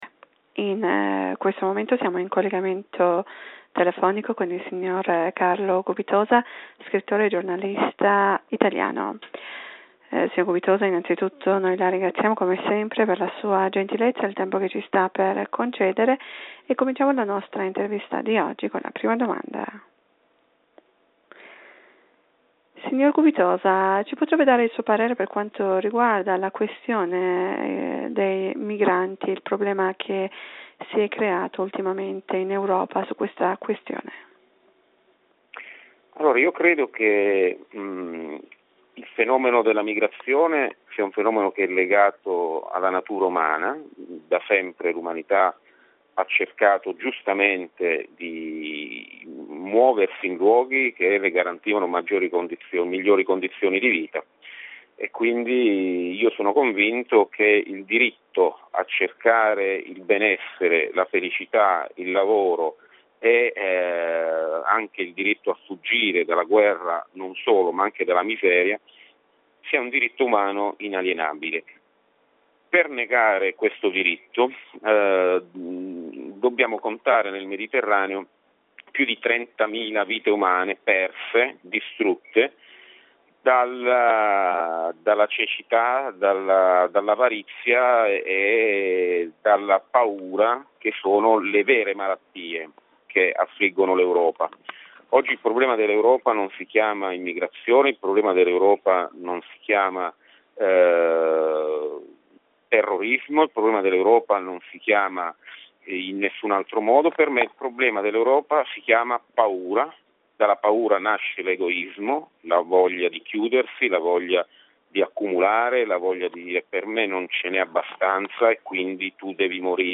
in un'intervista telefonica alla Radio Italia.